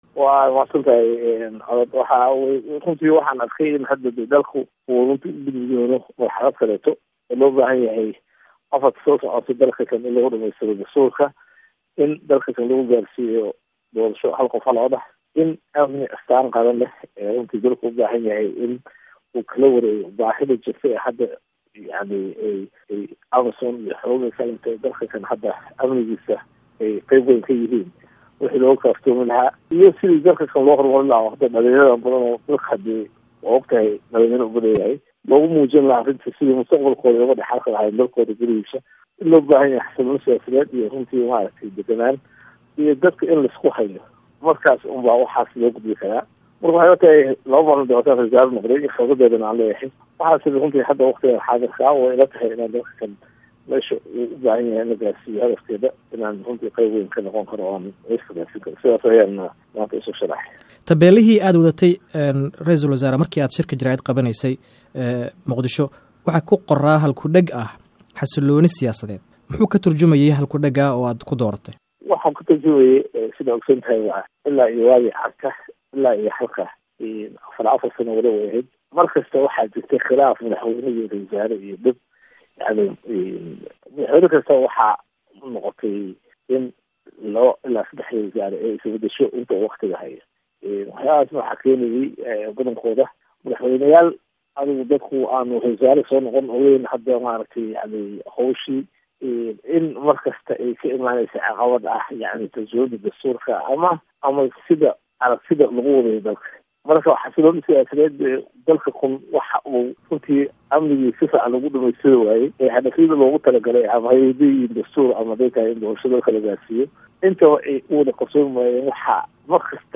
Wareysi: Ra'iisul Wasaare Cumar